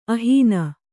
♪ ahīna